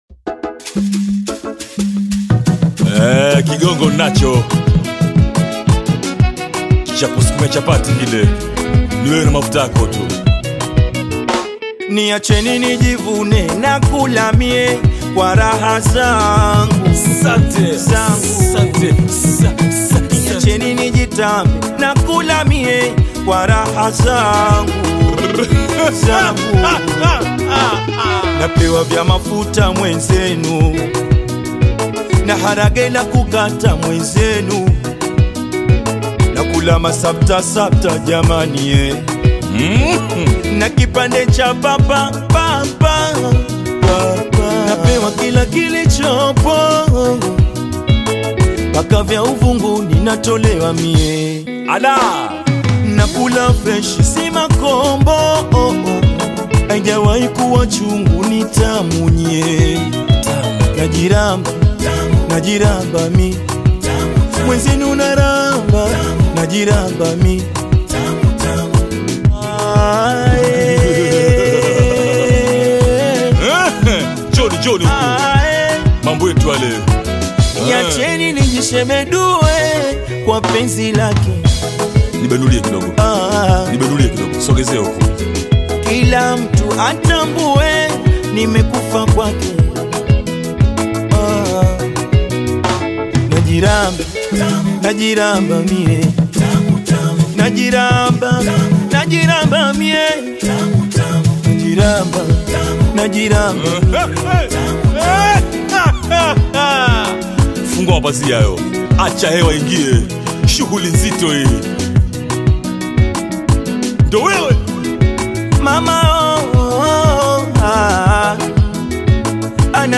BAIKOKO TANGA